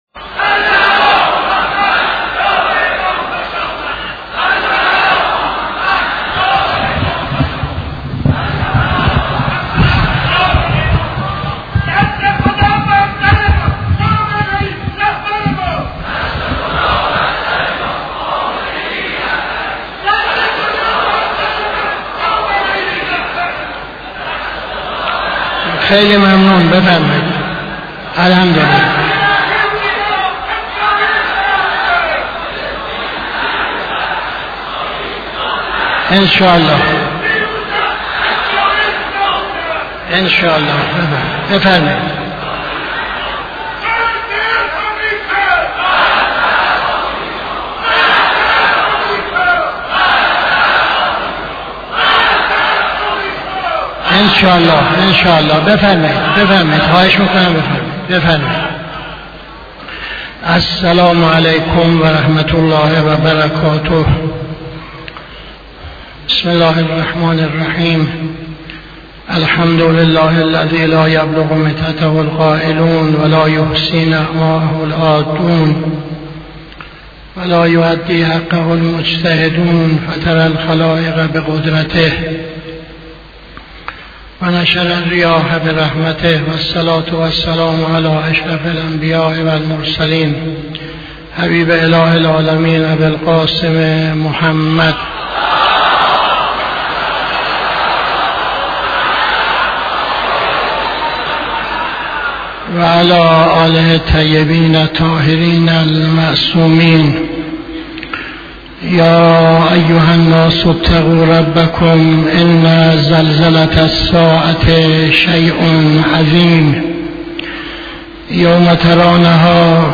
خطبه اول نماز جمعه 14-10-80